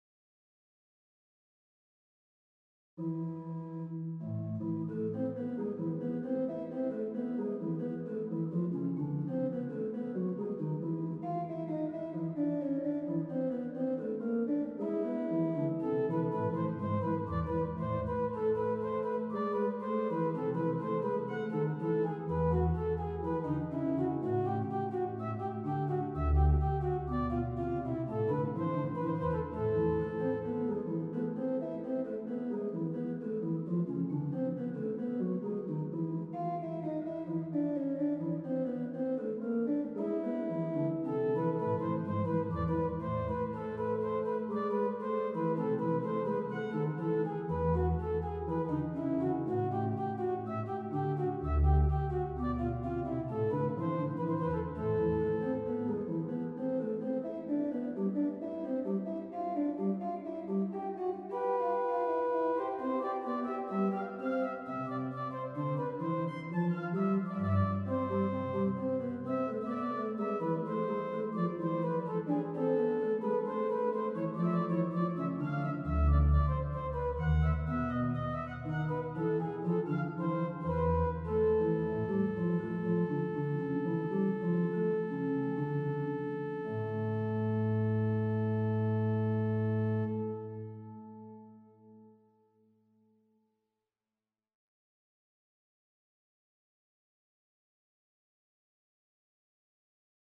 The recording was done on the sample set of the Holzey organ of St. Peter and Paul in Weissenau by Prospectum.